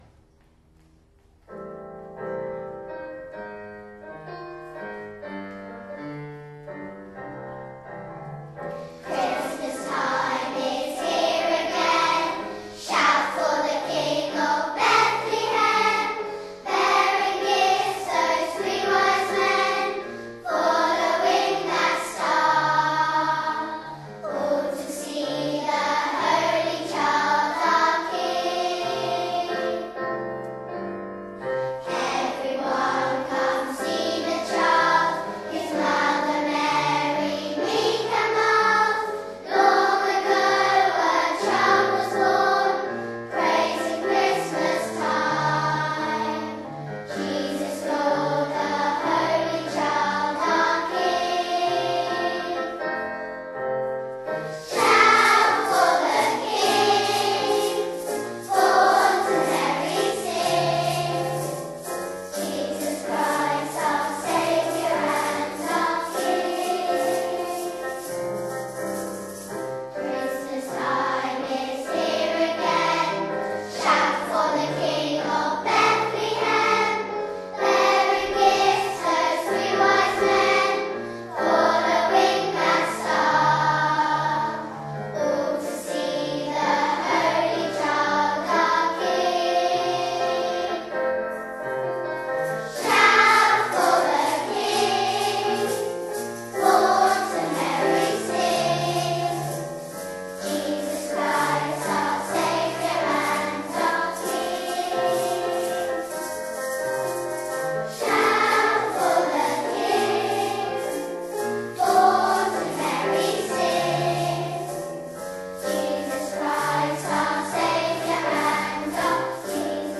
A joyous and vibrant carol for junior choir and piano.